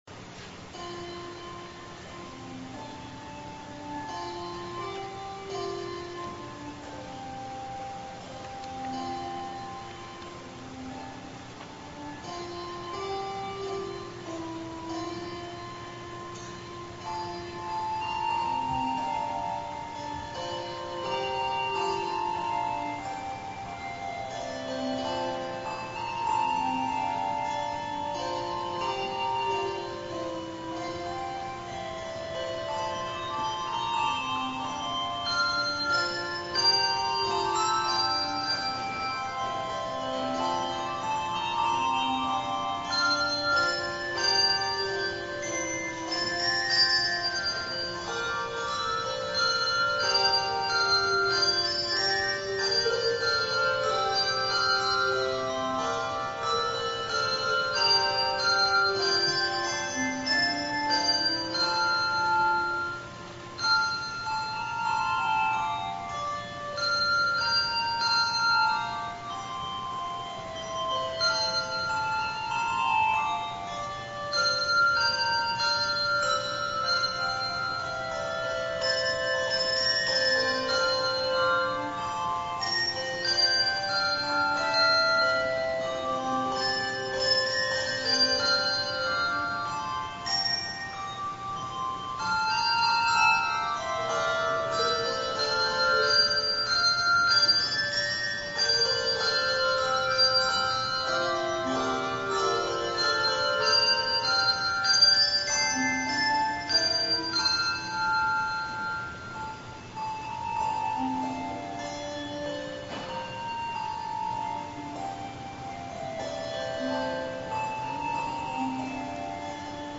Second Reformed Bell Choir plays "Chaconne for Bells" by Hal Hopson
Handbell Music